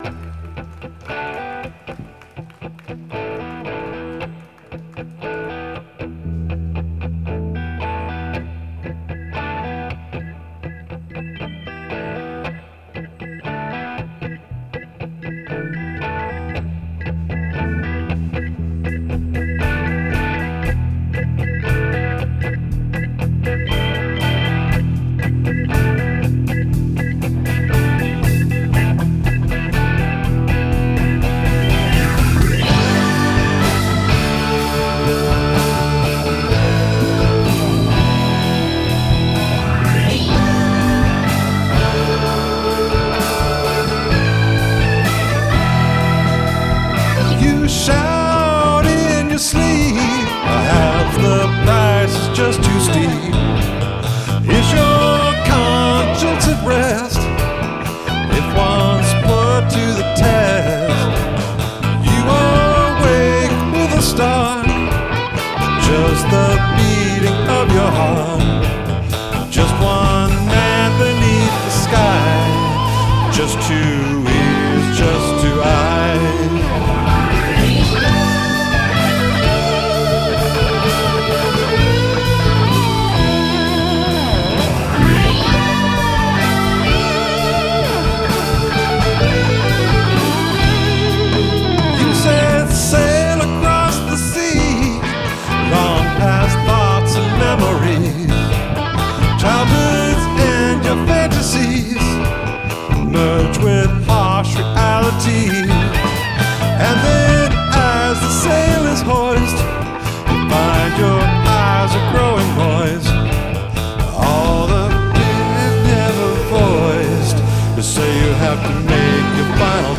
Seattle Peace Concert (Seattle) - 7/11/10